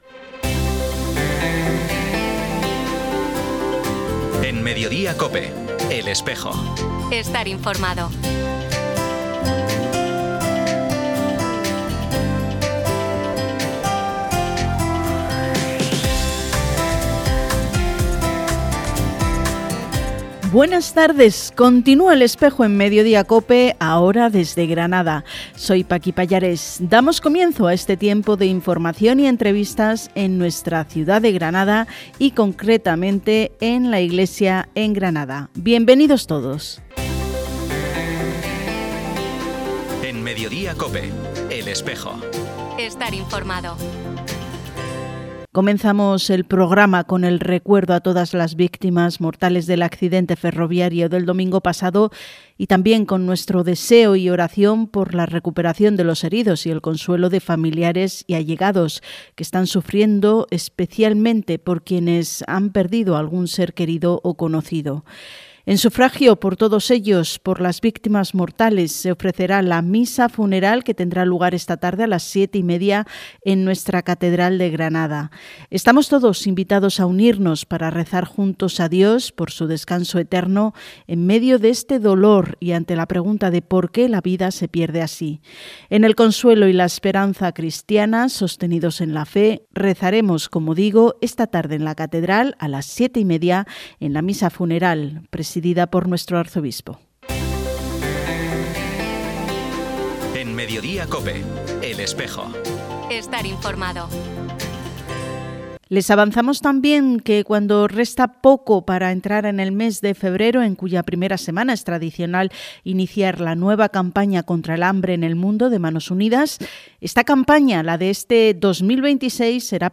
Entrevista sobre la libertad religiosa en el mundo, en “El Espejo Granada” - Archidiócesis de Granada
Programa emitido en COPE Granada el viernes 23 de enero de 2026.